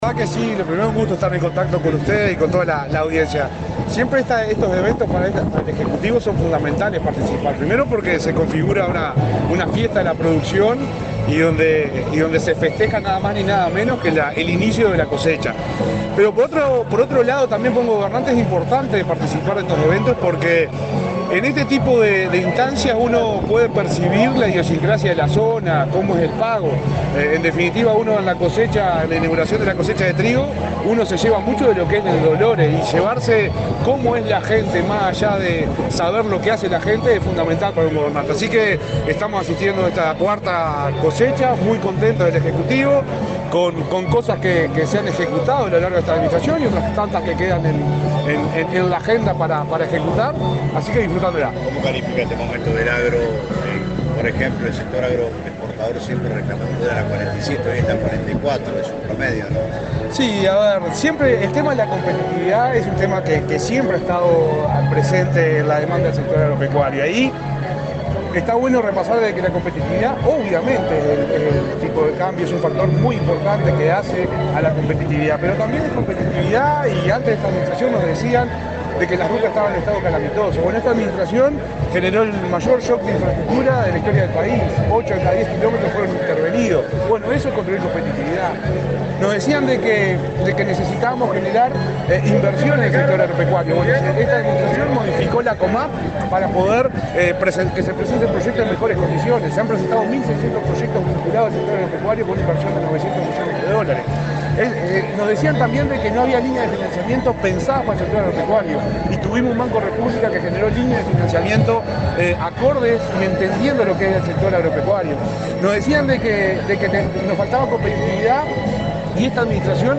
Declaraciones del subsecretario de Ganadería, Ignacio Buffa
Este viernes 15, el subsecretario de Ganadería, Ignacio Buffa, dialogó con la prensa, antes de participar en la inauguración de la cosecha del trigo,